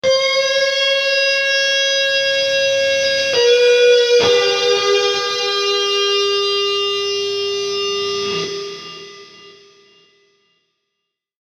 反转音乐
描述：我正在寻找创造一种声音，它听起来像是反向但实际上并没有，希望效果有效！这个声音是由Pro Logic中的2个音阶Melodica制作的。
标签： 风琴 时间 音乐 陶醉 时间扭曲 时间旅行 点化 空间 反向
声道立体声